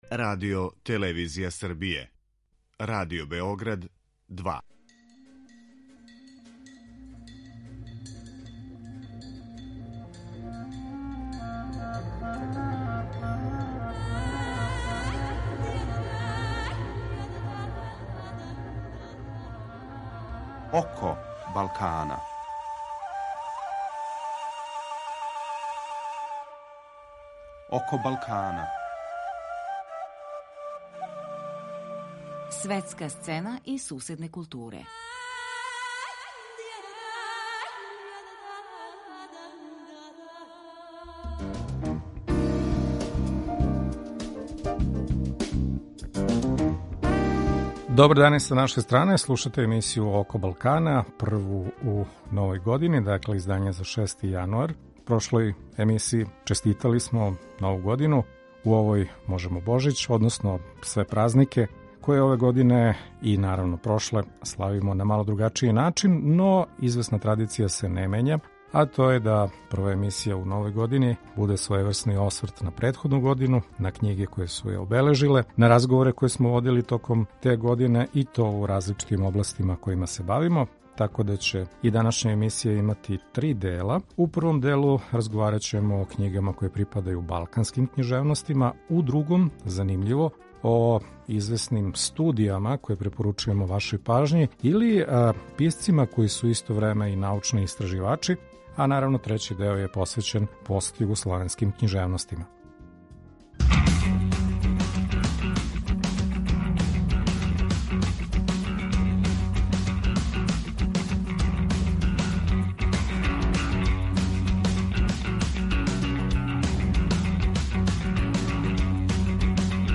У првом сегменту чућемо преводитељке или уреднике и уреднице прозних књига балканских аутора: Анилде Ибрахими, Аријана Леке, Филипа Флоријана, Мирче Елијадеа и Мирче Картарескуа.